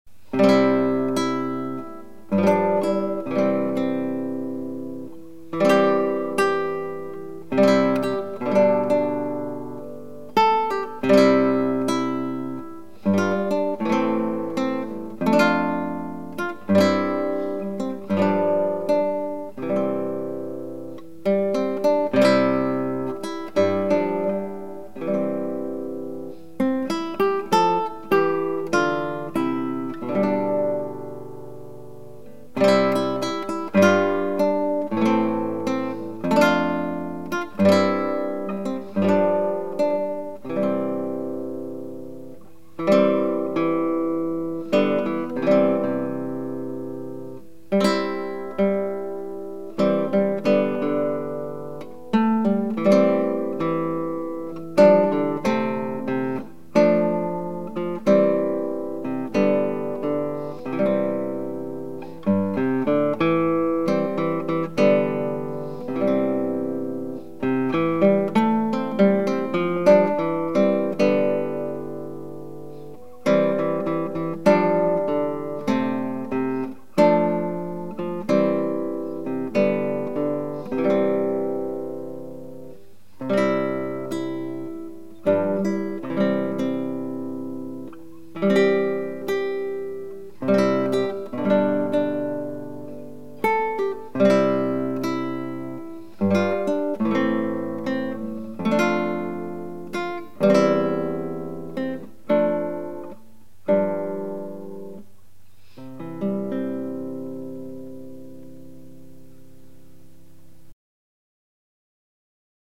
DIGITAL SHEET MUSIC - FINGERPICKING SOLO